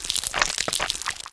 rifle_eyestalk1.wav